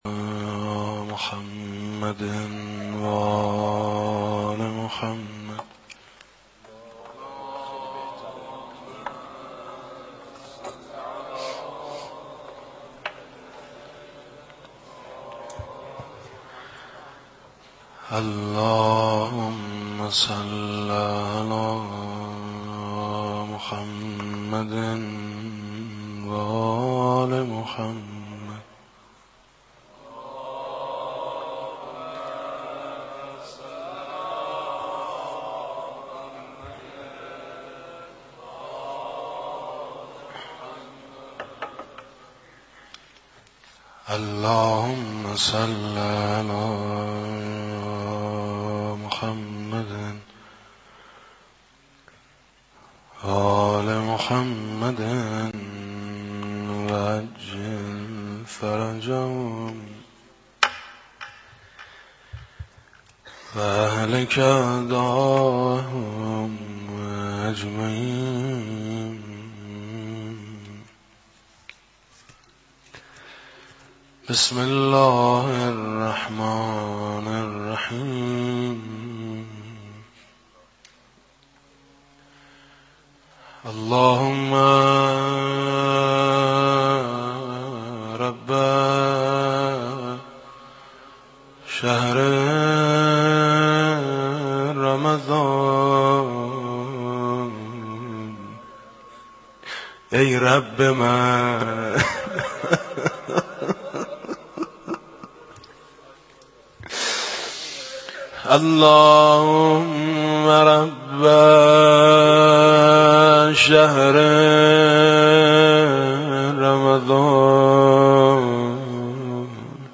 مداحی جدید رمضان